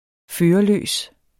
Udtale [ ˈføːʌˌløˀs ]